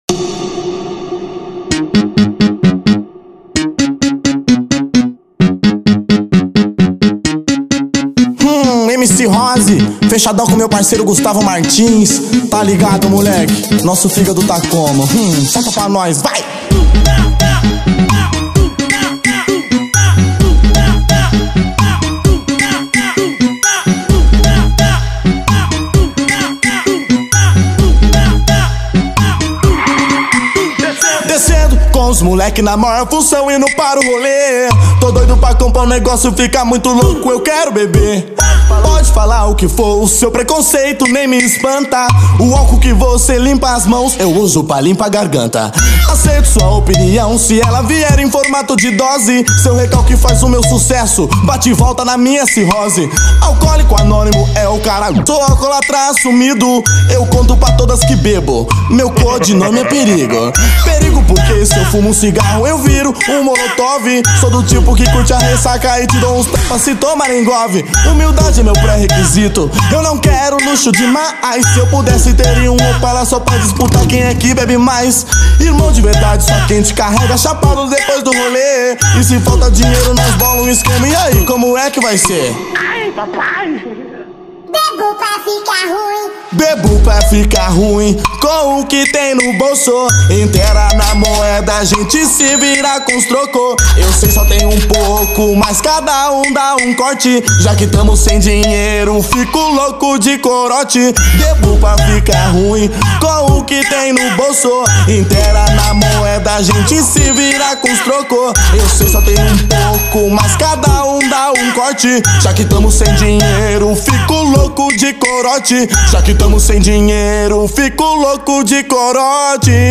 2024-08-22 19:57:13 Gênero: Funk Views